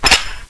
oldcocking2.wav